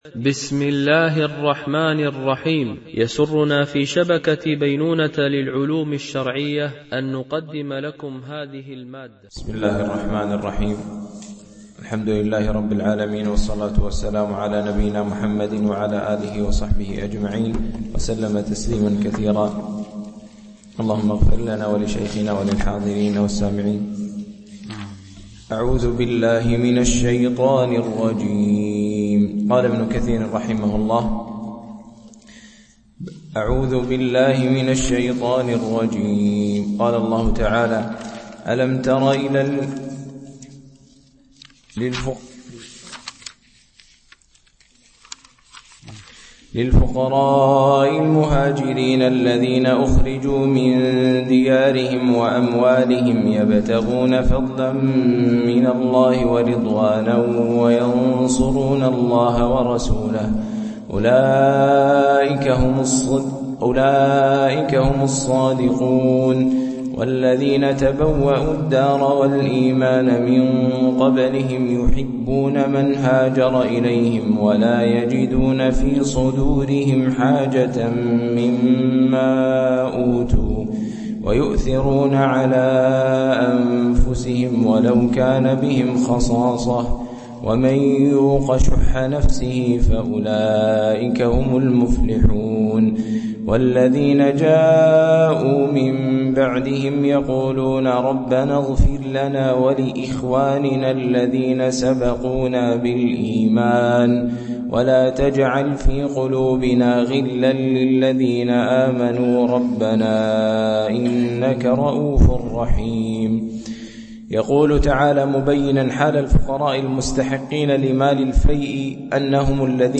شرح مختصر تفسير ابن كثير(عمدة التفسير) ـ الدرس 77 (تكملة سورة الحشر)
MP3 Mono 22kHz 32Kbps (CBR)